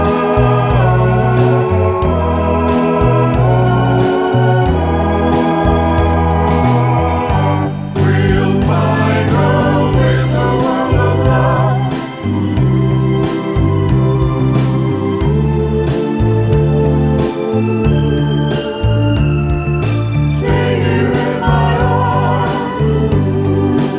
Two Semitones Down Crooners 3:19 Buy £1.50